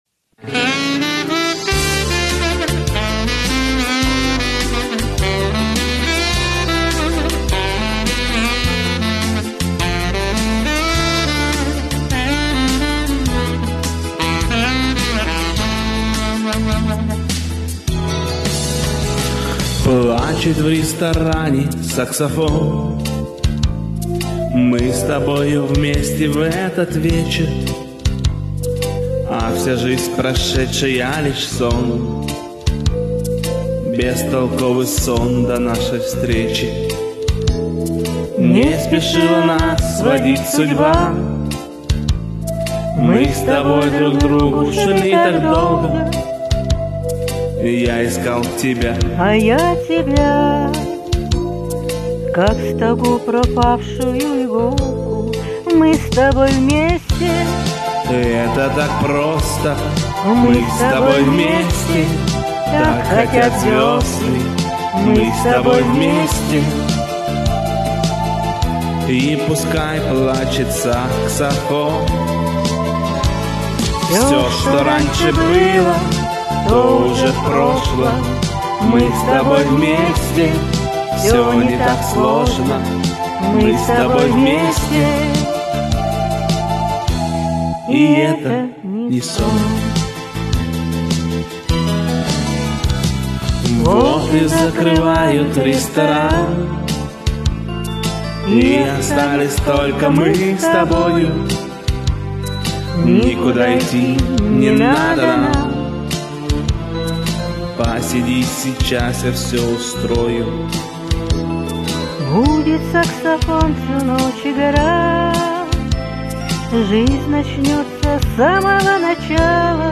Саксофон
Saksofon.mp3